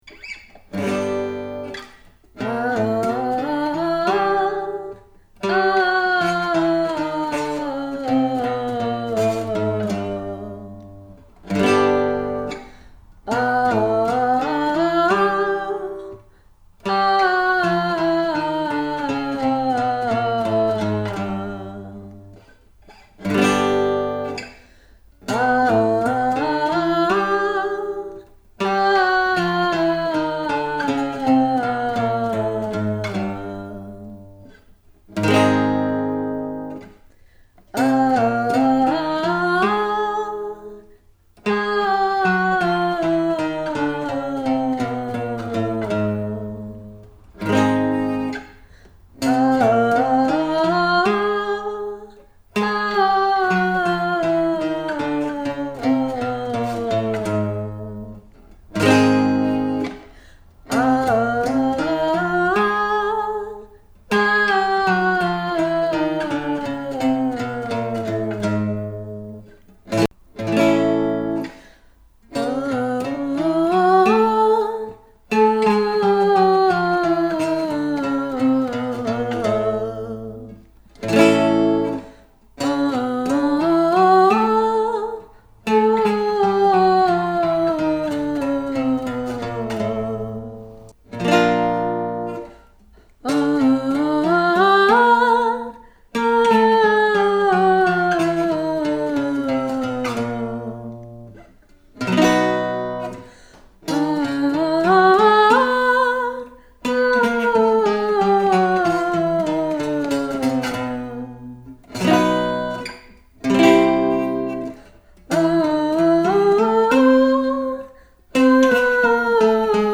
Major pentatonic up, chromatic down.
Backing-tracks-FYV-style-session-4-maj-pentatonic-up-chromatic-down.mp3